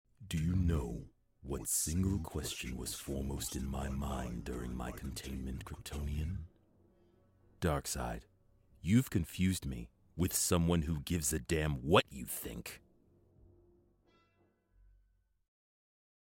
Narrator, VA